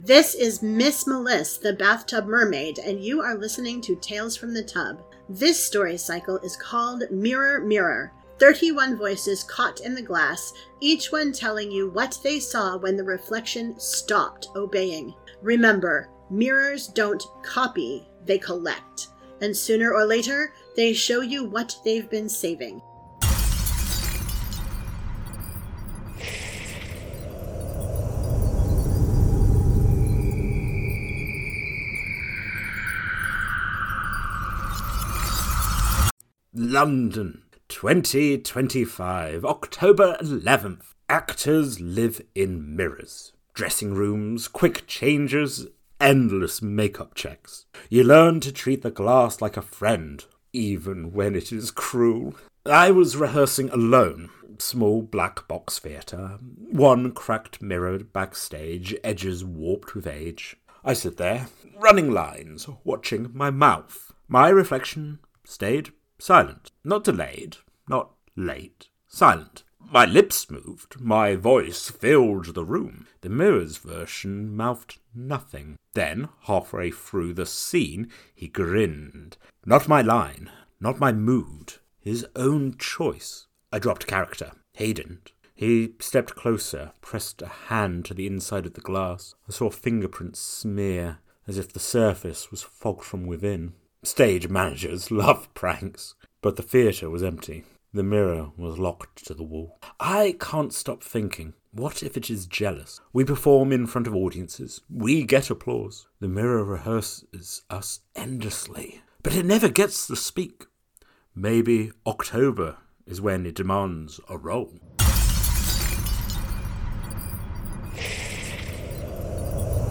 🎧 Mirror Mirror is a 31-day audio fiction cycle for October — one haunting voice each day.